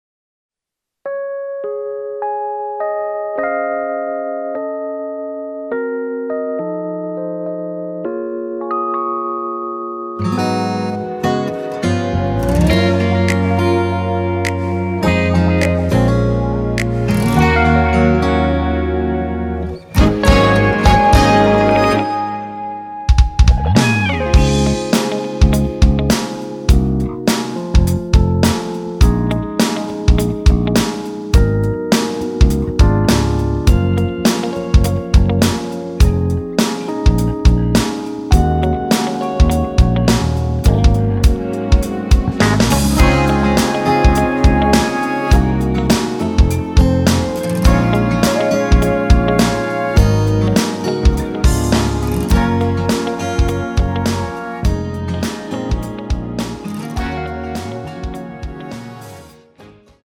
공식 음원 MR
Db
앞부분30초, 뒷부분30초씩 편집해서 올려 드리고 있습니다.